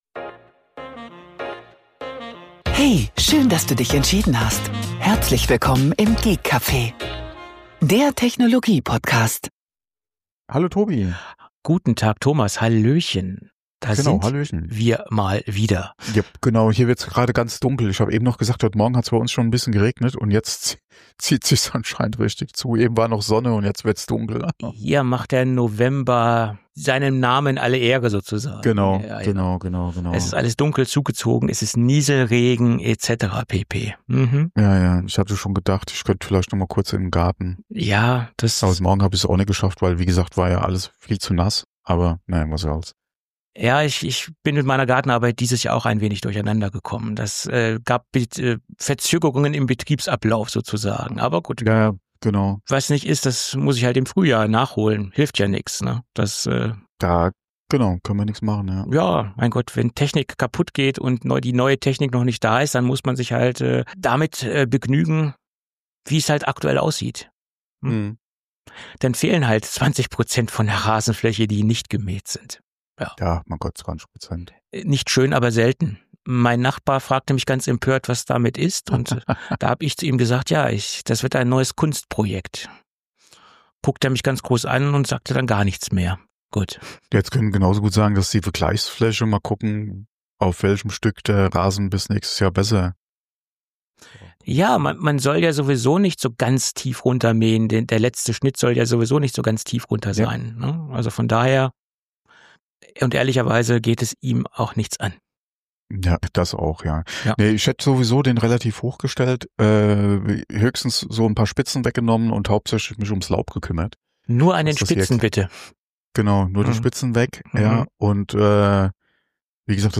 Die lockere Atmosphäre während der Aufnahme ist dem Konzept – oder eben dem Fehlen desselbigen zu verdanken.